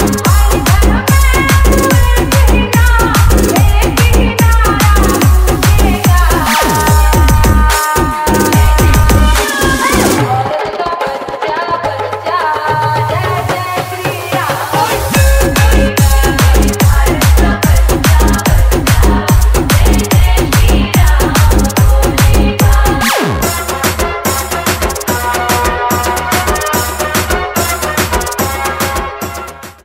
Bhakti Ringtones
Devotional Ringtones